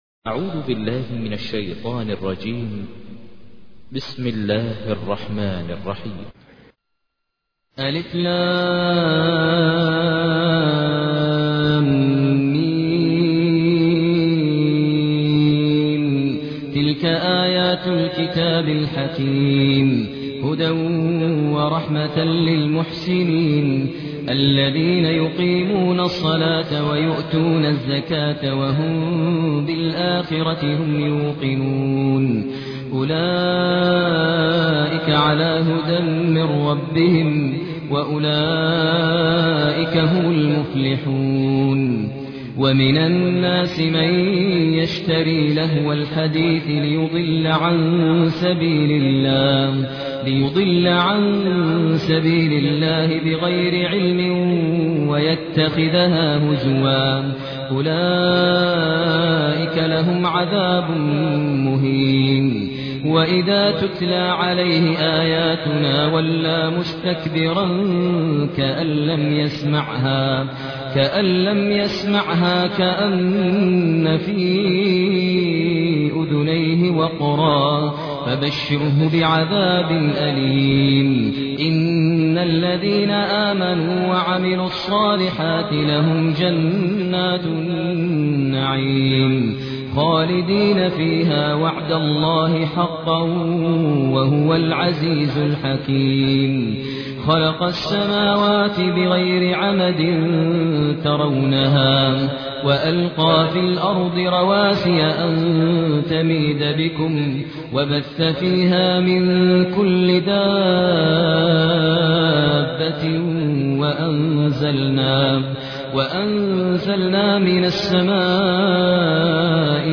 تحميل : 31. سورة لقمان / القارئ ماهر المعيقلي / القرآن الكريم / موقع يا حسين